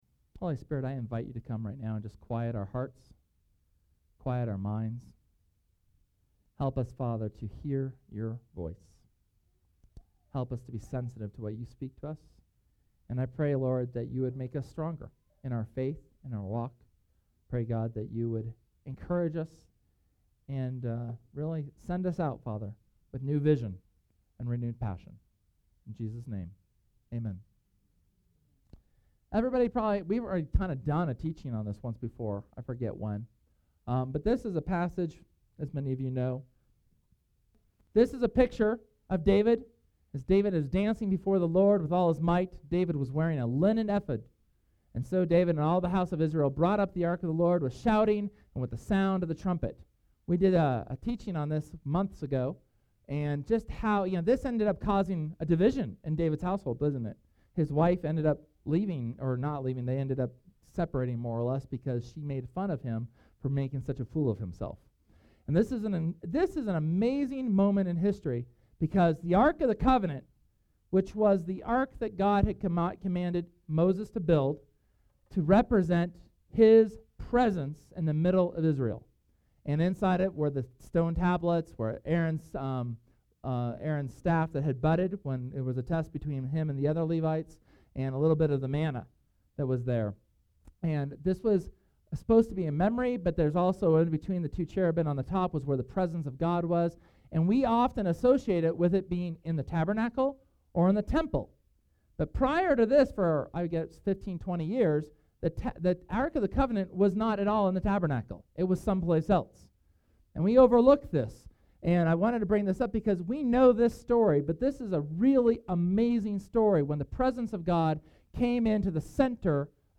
Sunday, Nov. 25th's sermon based on the first part of the life of Samuel the prophet from 1 Samuel.